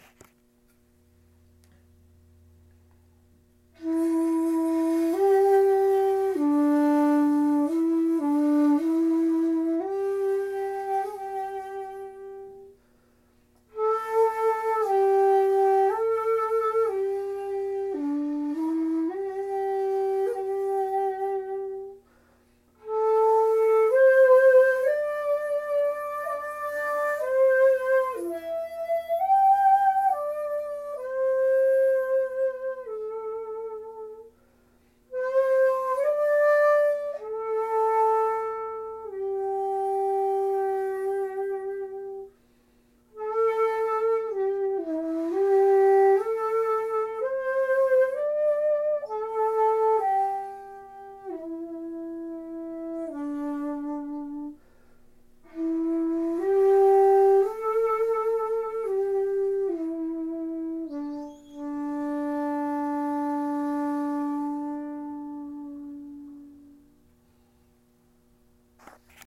次に、地無し一尺八寸管で吹いた「吉野山」をお聴き下さい。
326-4hassunyoshinoyama.mp3